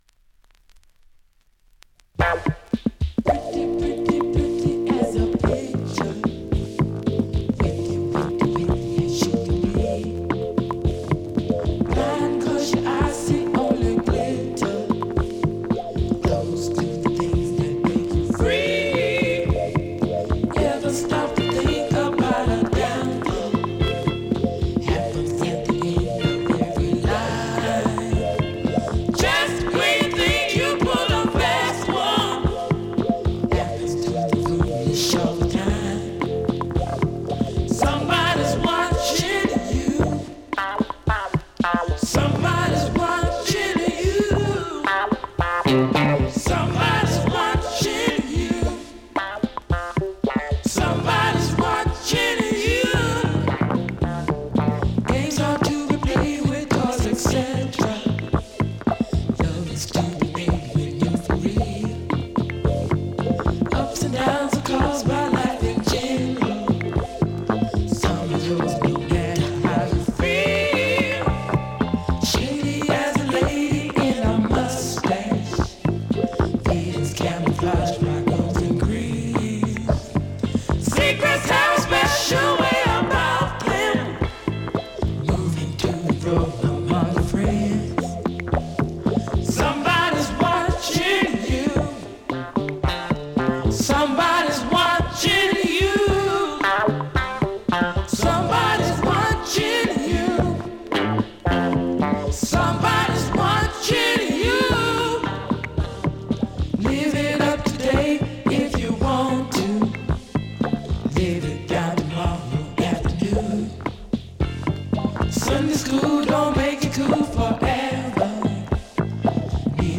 現物の試聴（両面すべて録音時間３分）できます。